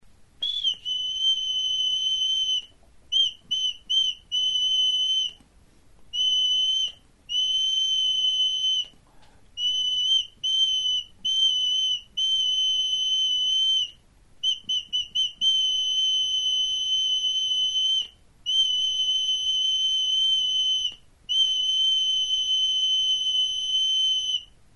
Instrumentos de músicaTXULUBITA
Aerófonos -> Flautas -> Recta (de una mano) + flautillas
Grabado con este instrumento.
EUROPA -> EUSKAL HERRIA
Lizar makilarekin egindako txulubita da.